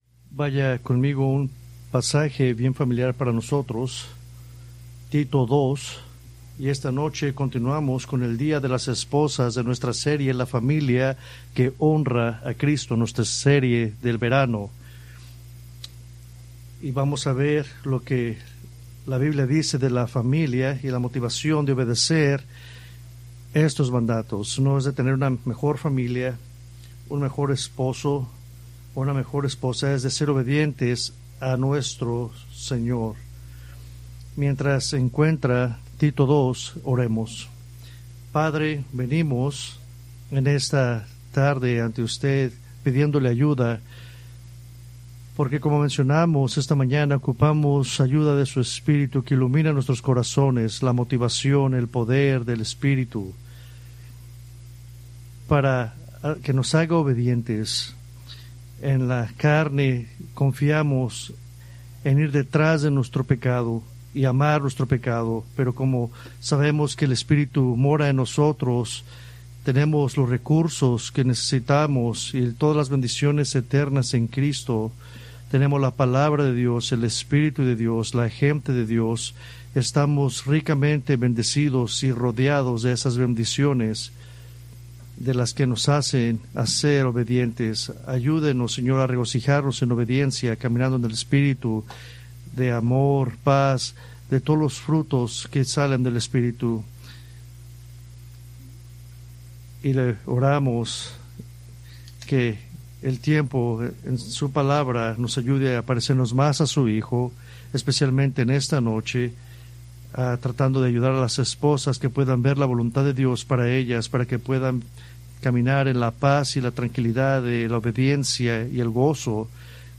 Preached August 10, 2025 from Escrituras seleccionadas